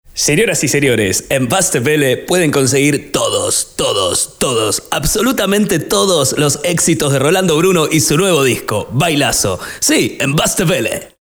Werbesprüchen im Stile der südamerikanischen Radiosprecher